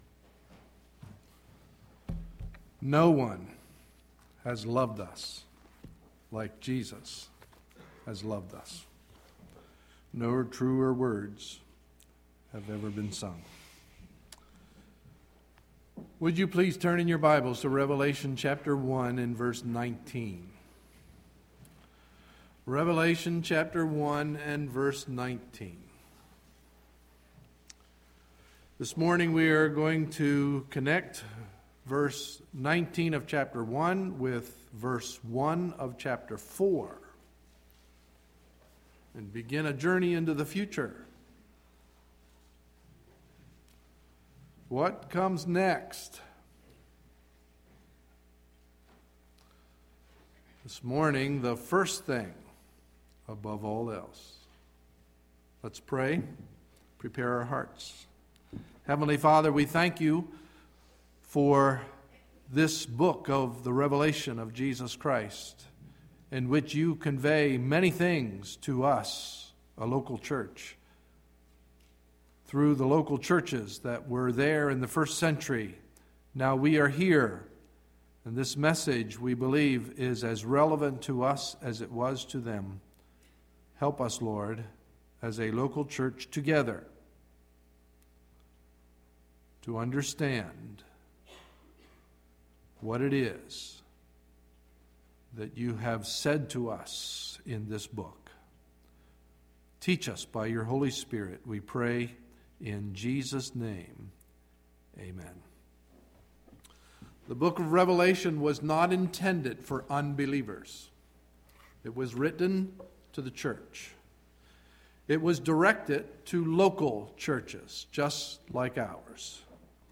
Sunday, May 1, 2011 – Morning Message